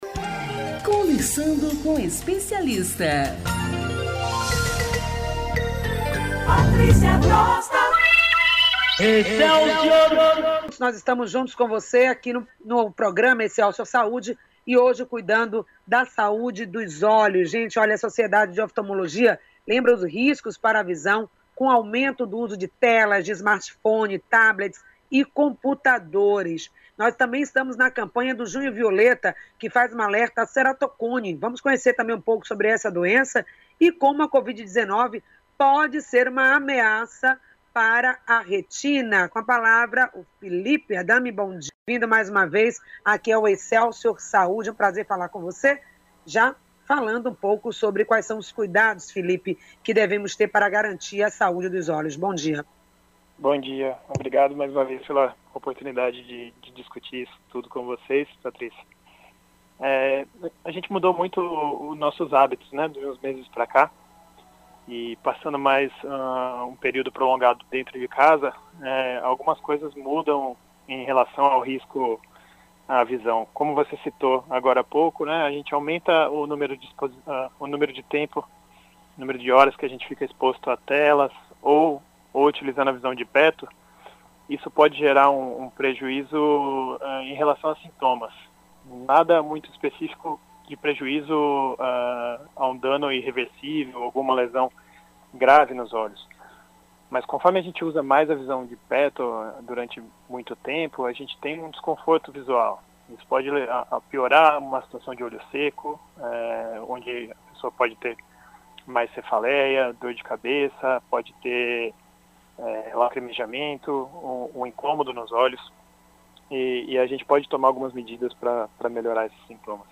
30.06-Saúde-ocular-na-Pandemia-Entrevista.mp3